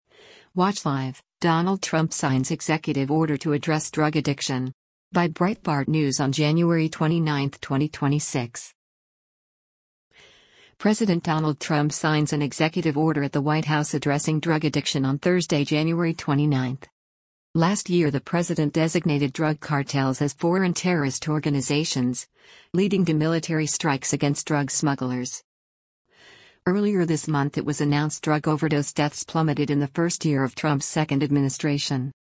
President Donald Trump signs an executive order at the White House addressing drug addiction on Thursday, January 29.